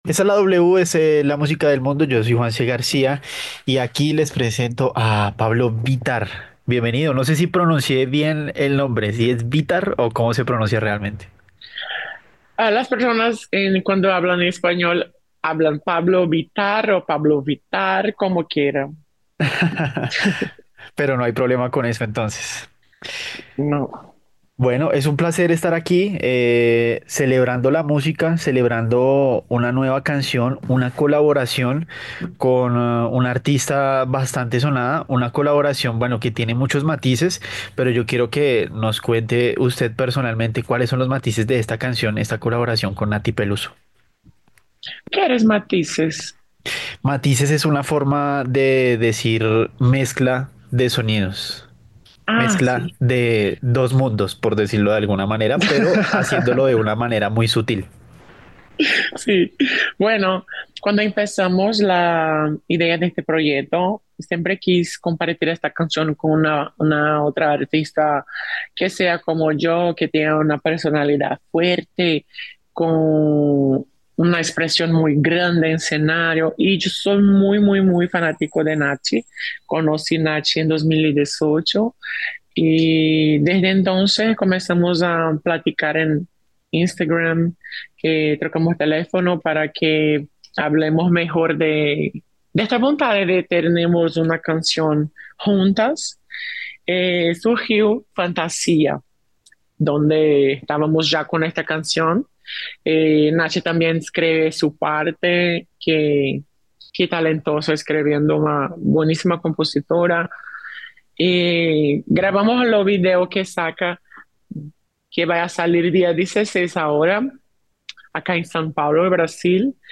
La cantante brasileña Pabllo Vittar habló con W Radio sobre su más reciente colaboración con Nathy Peluso, una canción cargada de identidad, libertad y conexión emocional que representa un nuevo capítulo en su carrera artística y en el diálogo cultural del pop latino.